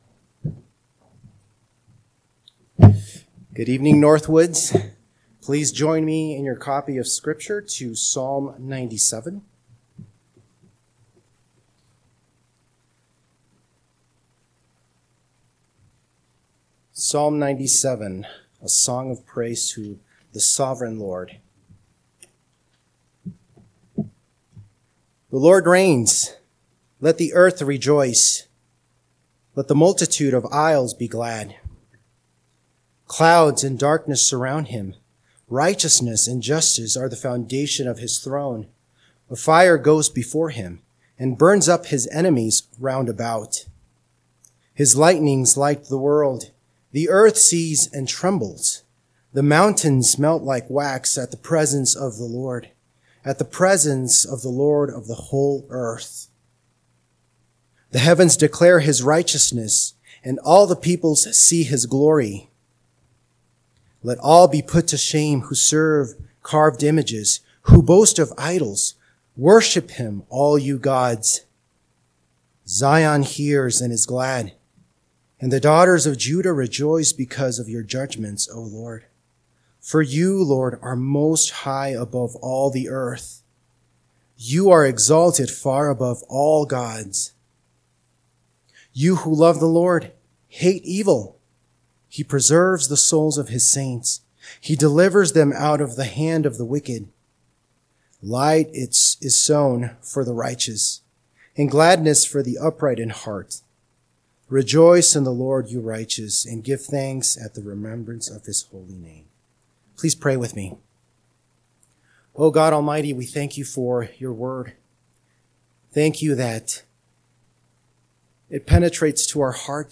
PM Sermon – 6/15/2025 – Psalm 97 – Northwoods Sermons